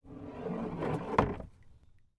На этой странице собраны звуки весла: плеск воды, ритмичные гребки и другие умиротворяющие аудиоэффекты.
Звук скатившегося с края лодки весла внутрь